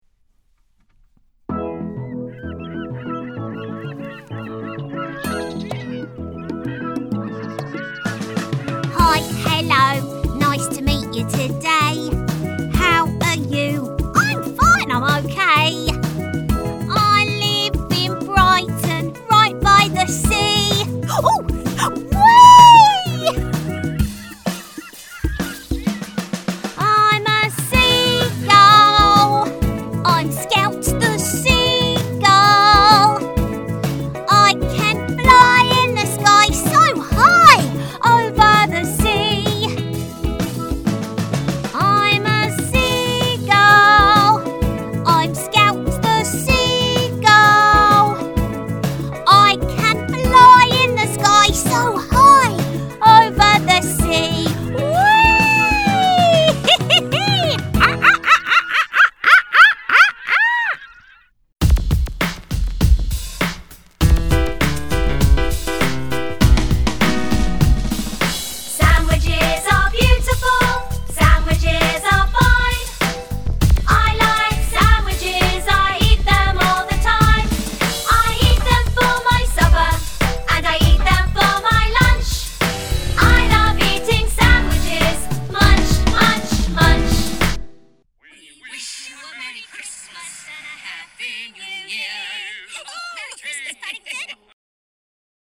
Singing Showreel
Female